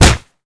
kick_heavy_impact_02.wav